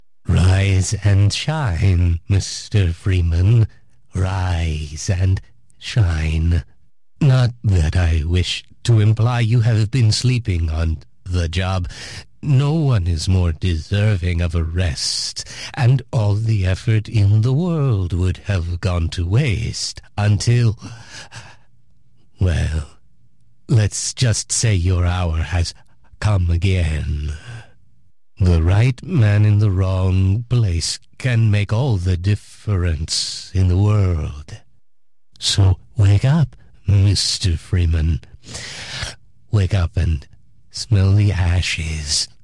The Suited Man zooms off into the distance as a Train materialises around Gordon. The blast of the Engine's horn is heard as the train pulls into an unknown station...